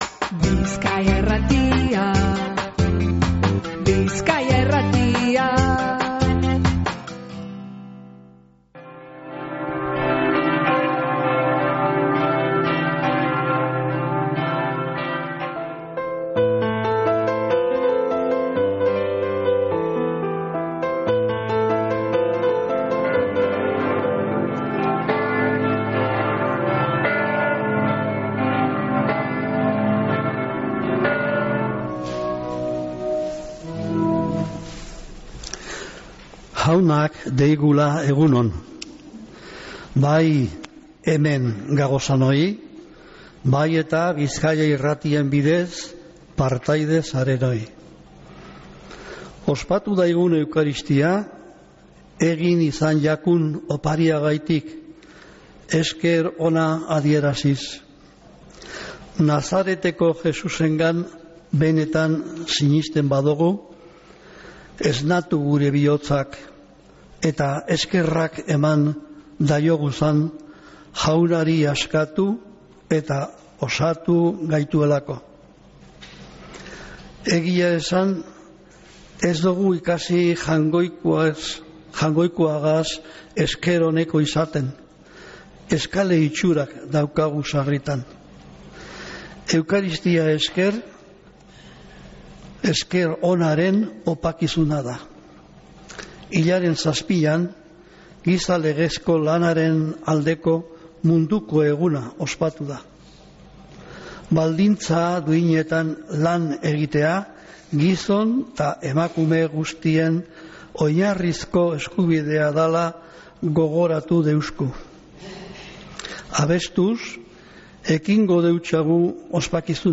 Mezea San Felicisimotik | Bizkaia Irratia